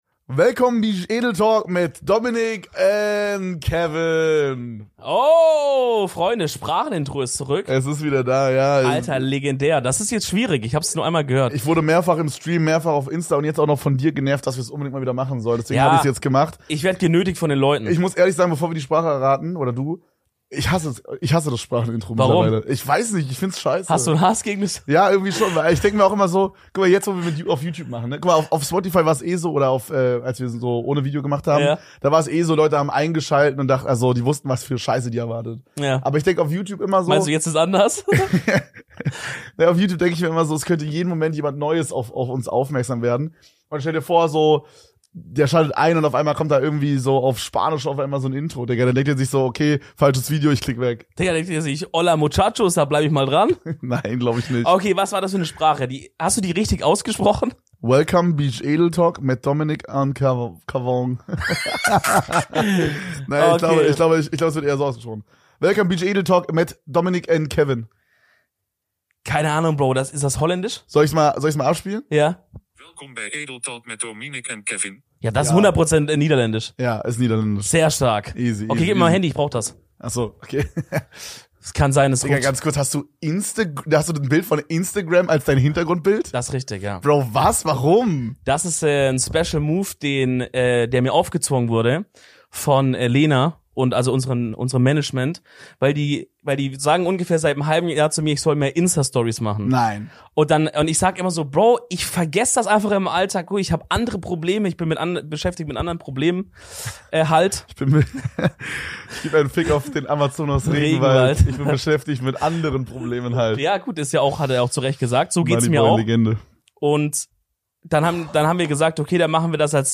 Nach zwei spannenden Gastfolgen sind wir heute wieder zu Zweit für euch im Studio und reden über alles, was die letzte Zeit bei uns abging.